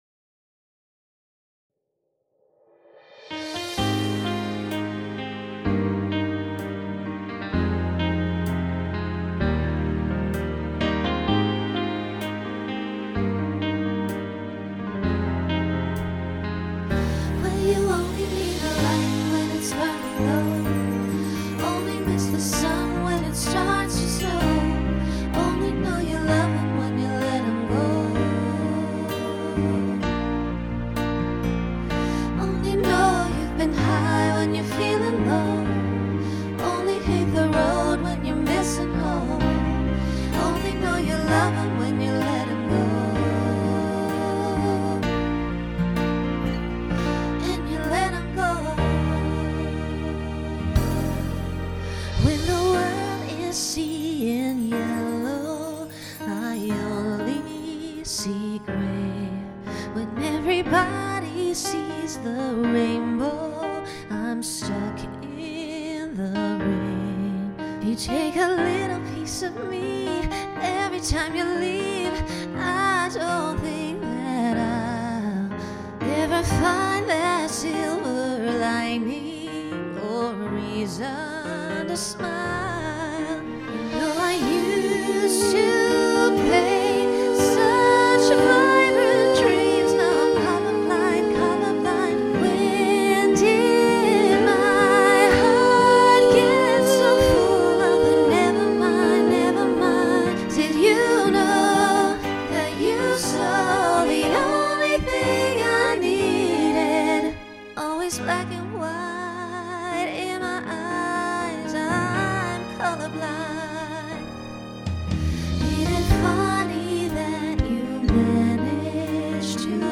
Voicing SSA Instrumental combo Genre Pop/Dance
Function Ballad